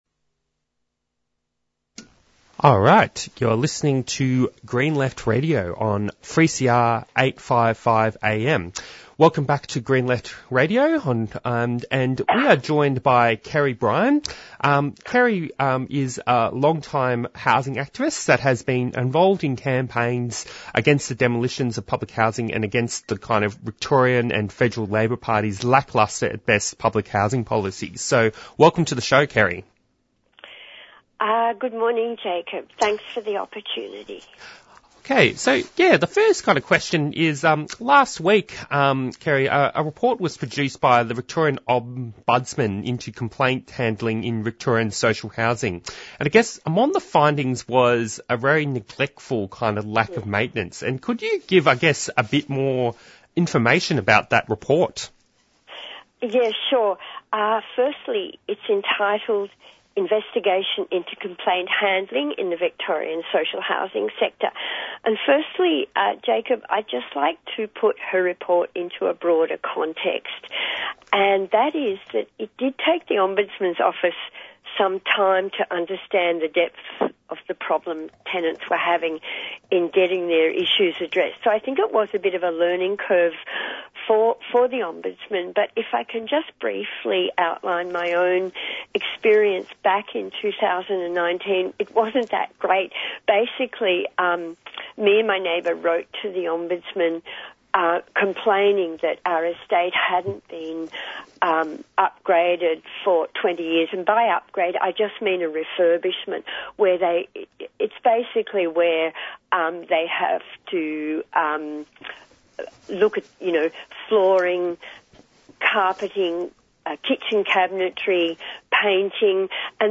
Newsreports
Interviews and Discussions